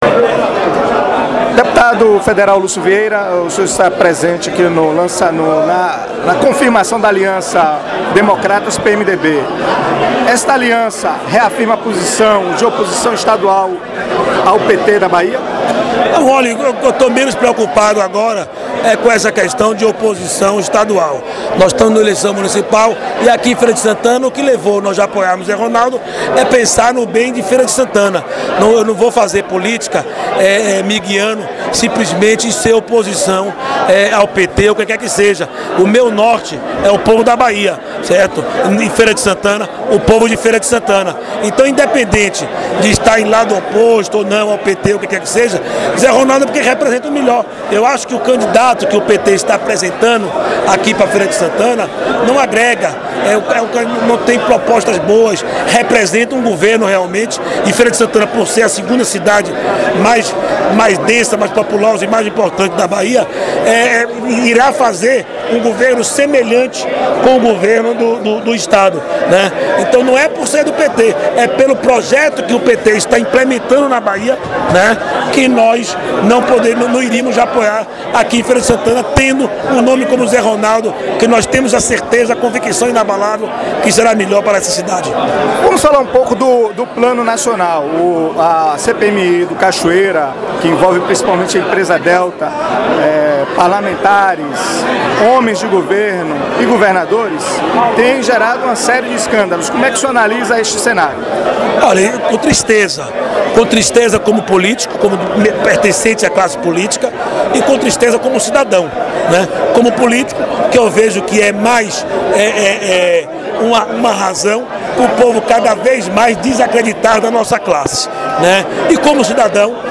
Lucio-Vieira-Entrevista-exclusiva.mp3